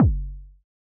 RDM_Raw_SY1-Kick03.wav